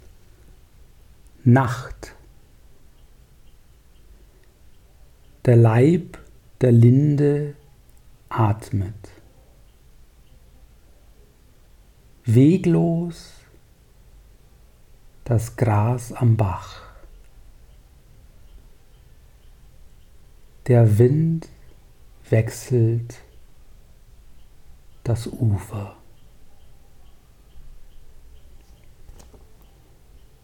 Lesung
Bis wieder Lesungen möglich werden, hier einige gesprochene Verse.